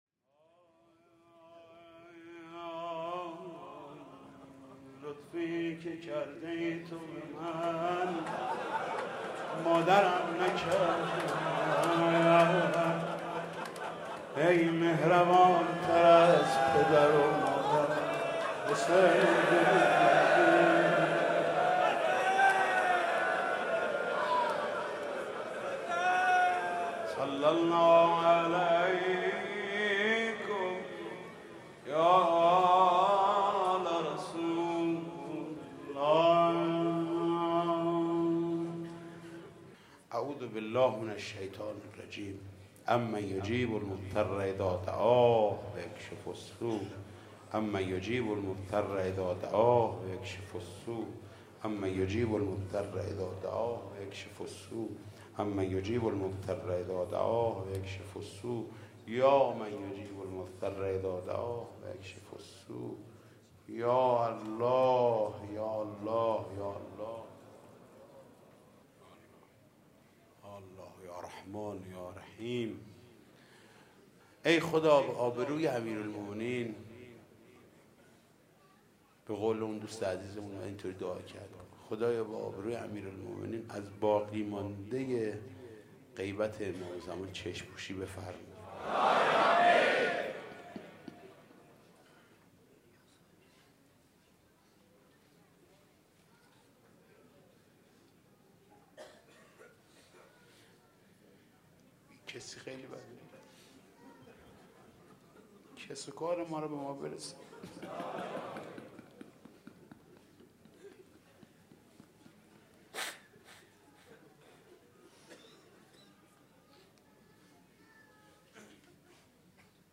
صوت مداحی
بخش یازدهم - مناجات [حاج محمود کریمی]  6:39